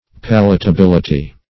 palatability - definition of palatability - synonyms, pronunciation, spelling from Free Dictionary
Palatability \Pal`a*ta*bil"i*ty\, n.